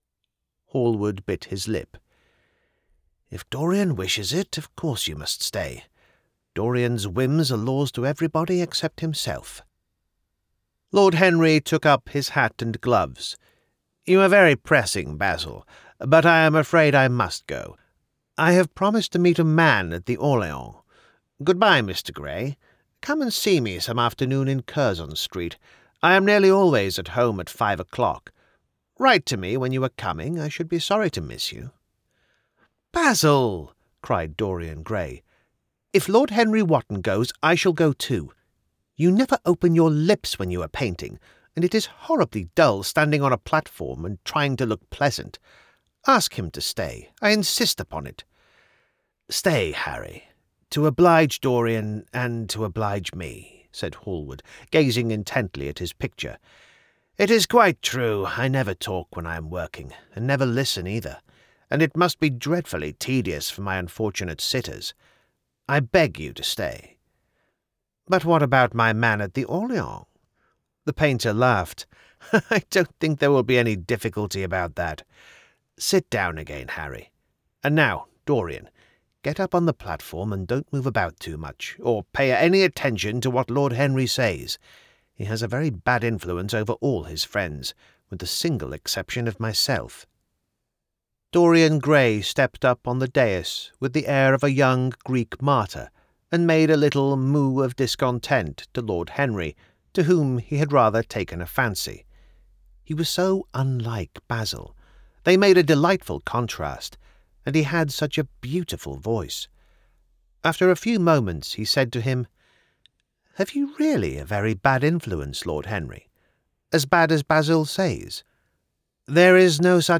A Selection of Audiobook Samples